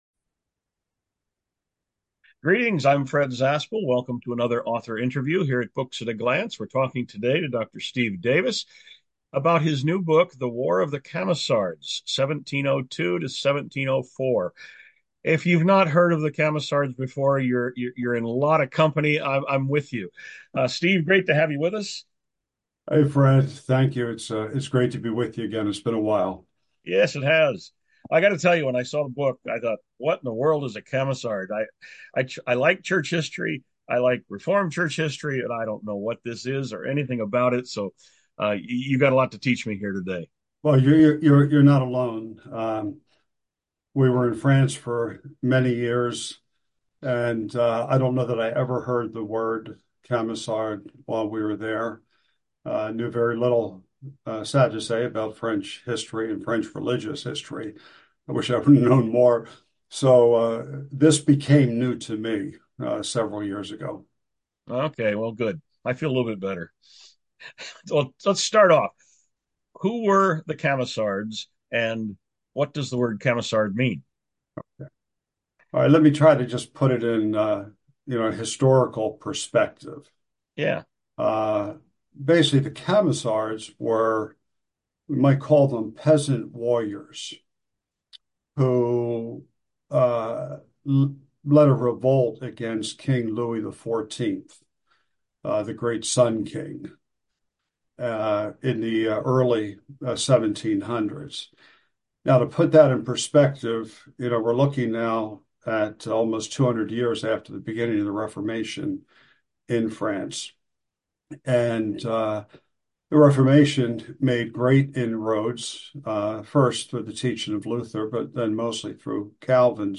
An Author Interview from Books At a Glance
Sample Audio Interview: